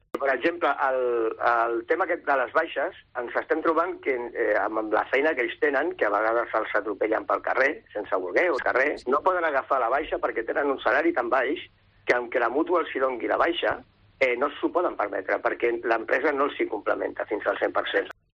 portavoz sindical